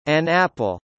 など、2つの別々の単語であるが、リンキングをすることにより、1つの単語の発音のようであるかのように聞こえます。